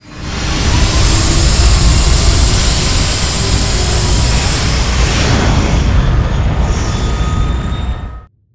ships / Movement / launch1.wav
launch1.wav